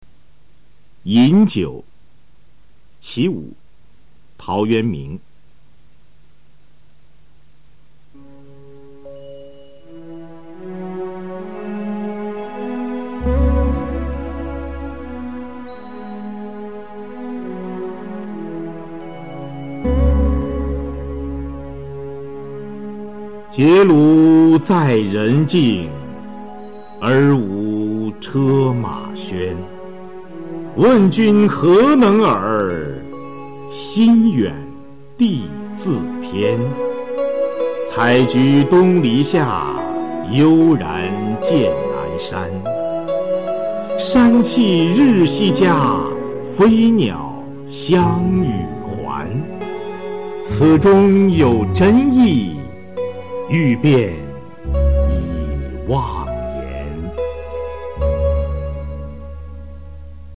陶潜《饮酒（其五）》原文和译文（含赏析及MP3朗读）　/ 陶潜